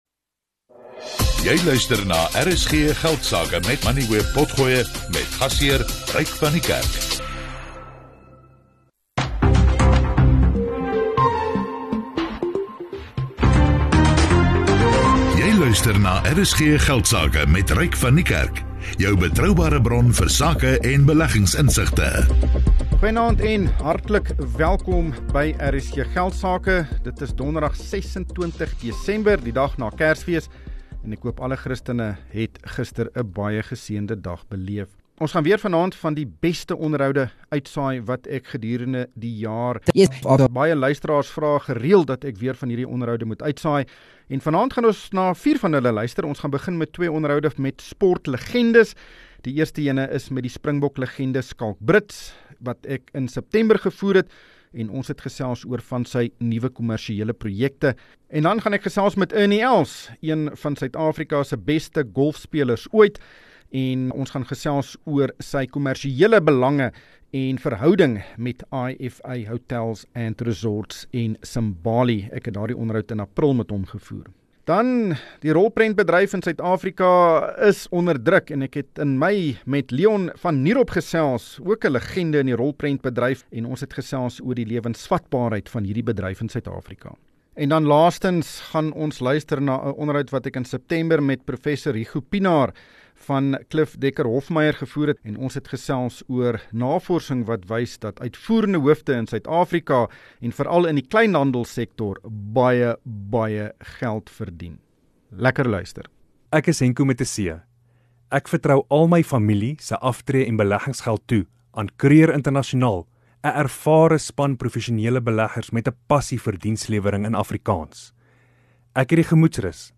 RSG Geldsake is die grootste sakeprogram in Suid Afrika. Dit fokus op belangrike sakenuus, menings en beleggingsinsigte. RSG Geldsake word elke weeksdag tussen 18:10 en 19:00 op RSG (101 – 104 FM) uitgesaai.